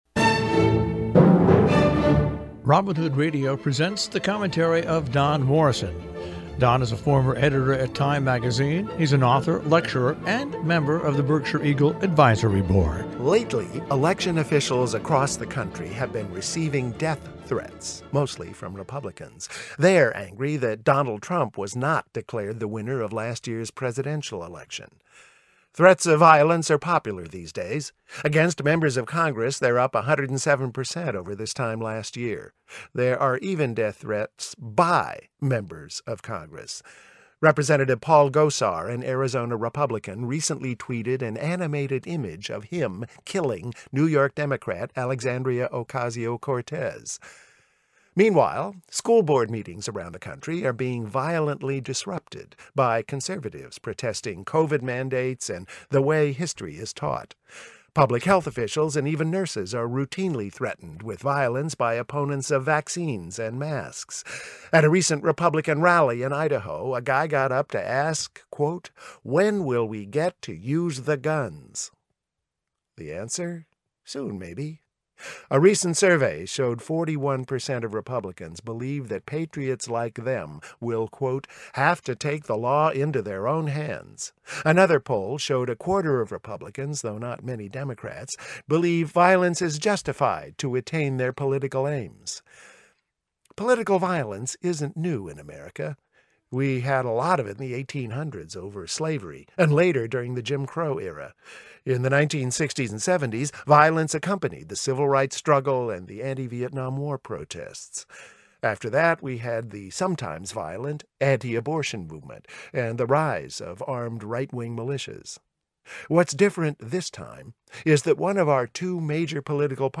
Commentary: There Will Be Blood